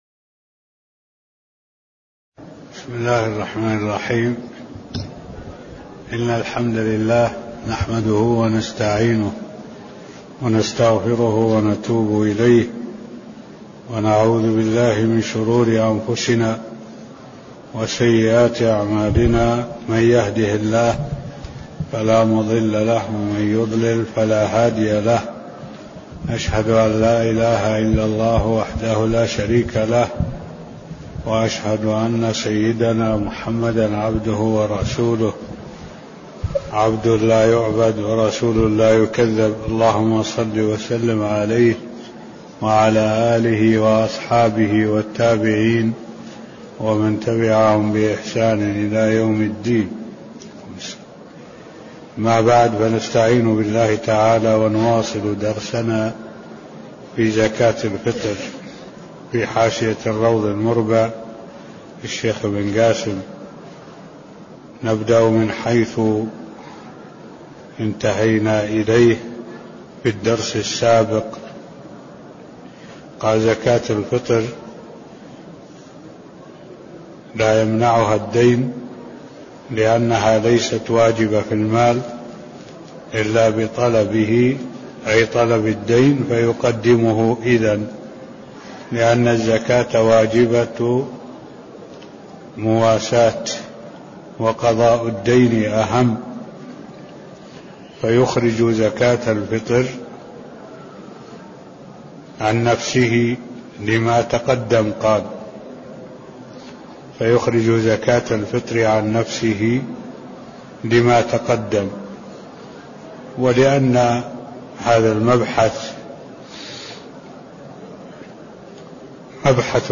تاريخ النشر ١٨ صفر ١٤٢٧ هـ المكان: المسجد النبوي الشيخ: معالي الشيخ الدكتور صالح بن عبد الله العبود معالي الشيخ الدكتور صالح بن عبد الله العبود قوله: زكاة الفطر لايمنعها الدين (002)